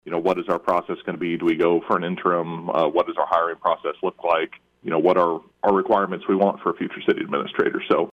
City Councilor Jeff Schneider joined the KFJB line yesterday to talk about why and what is next.